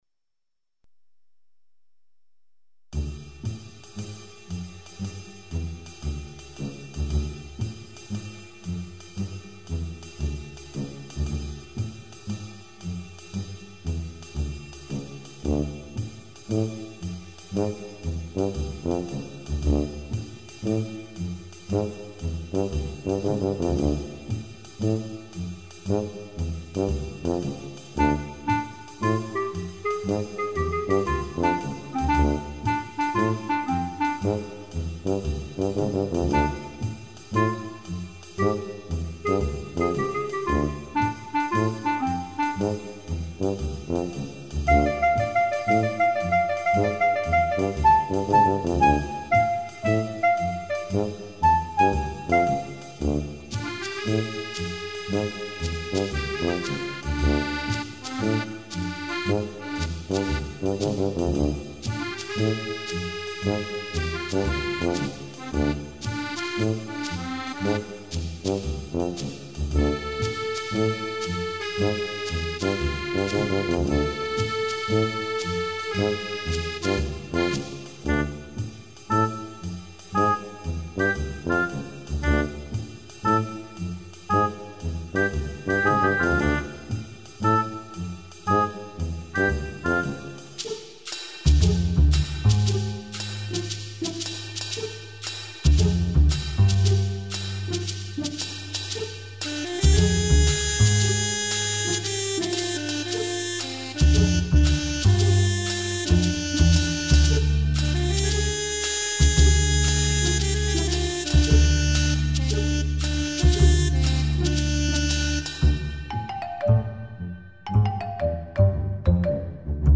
playful mysterious tone
Music / Game Music
Fun sounding track